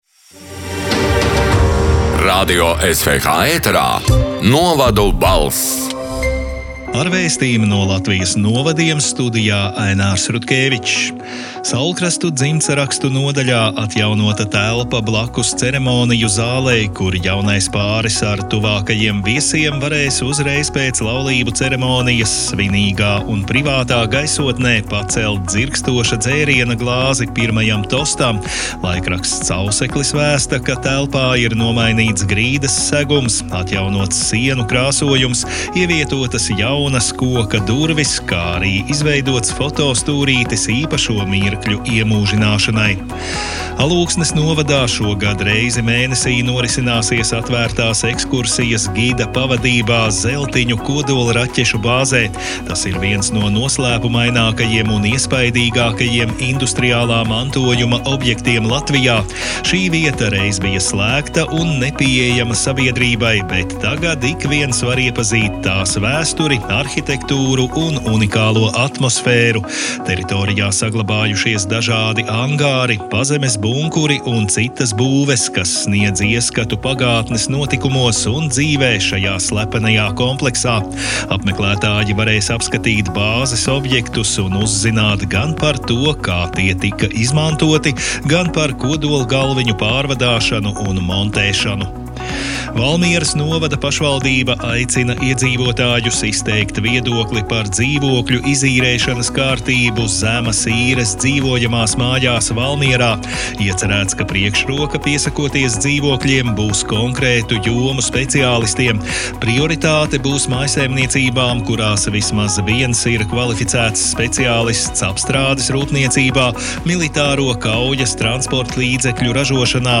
“Novadu balss” 19. marta ziņu raidījuma ieraksts: